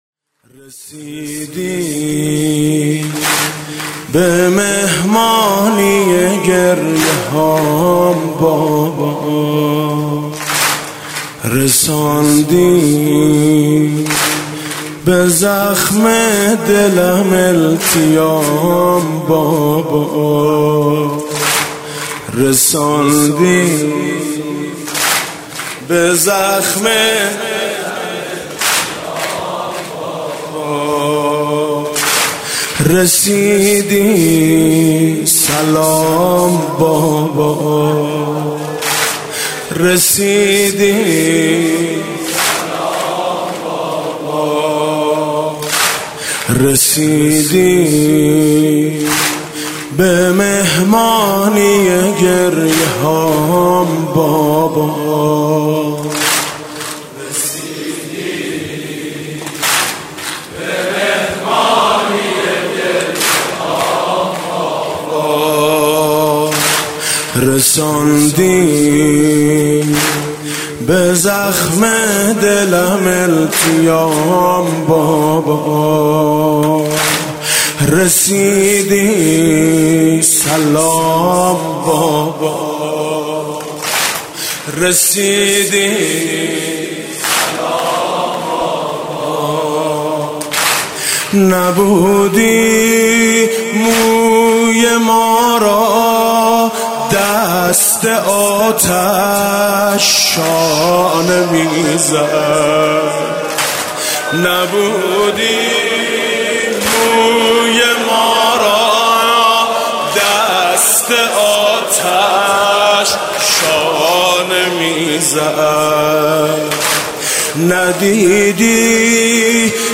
شب سوم محرم ۱۳۹۷
music-icon واحد: نبودی موی ما را دست آتش شانه میزد حاج میثم مطیعی